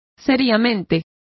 Complete with pronunciation of the translation of seriously.